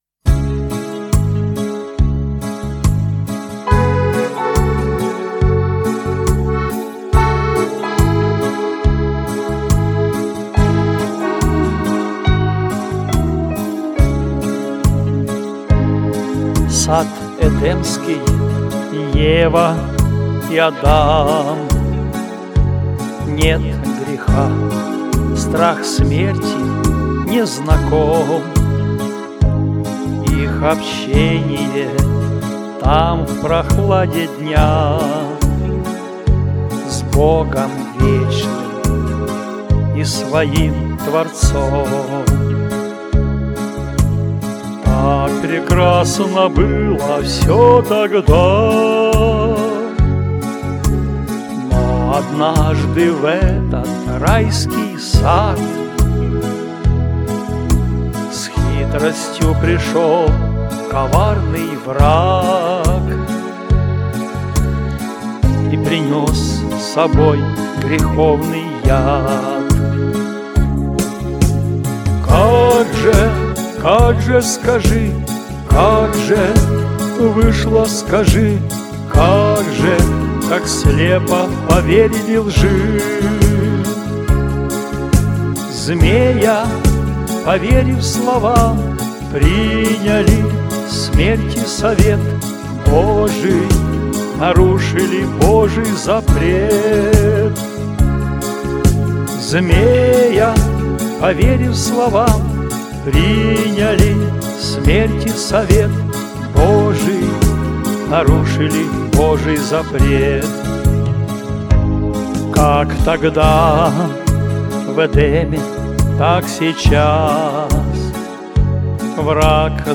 Главная » Файлы » Авторские песни.